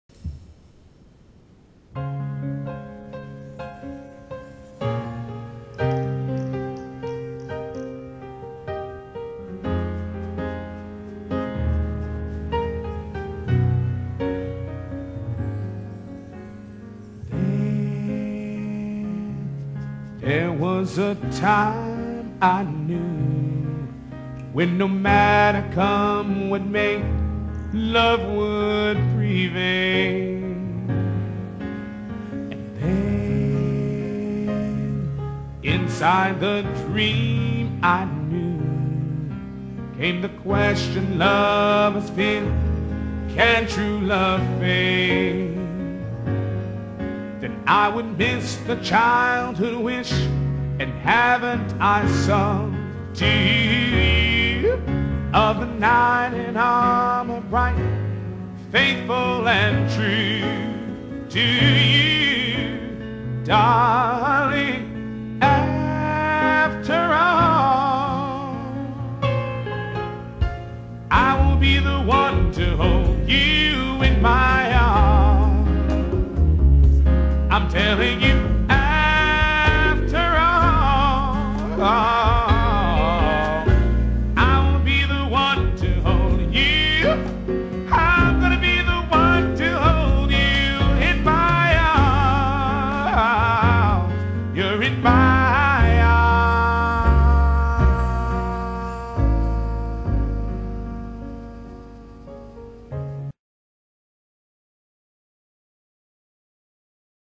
VOCALIST